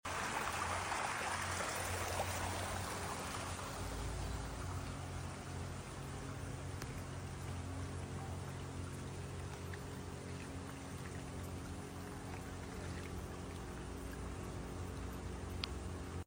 Plenty of flooding in Ashgrove, sound effects free download